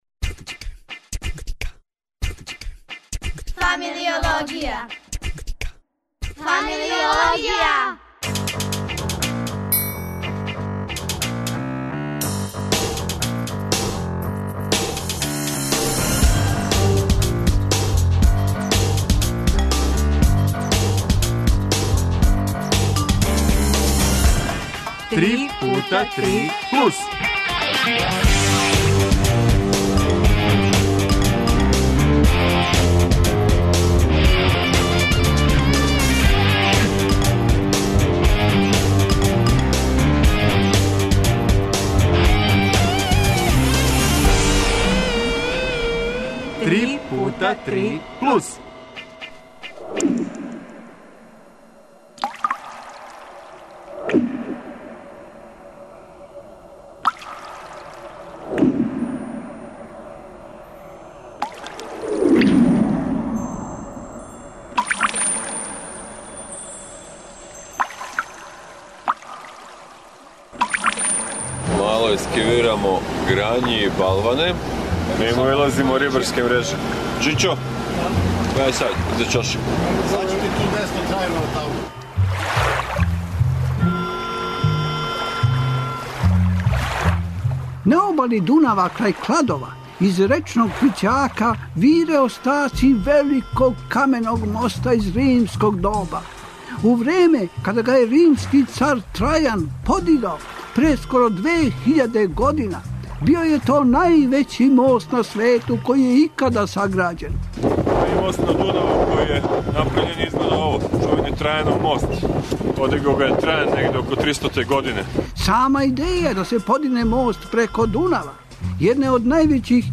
Гости су нам, у ствари, гости су нам домаћи: дечји хорови са диригенткињама.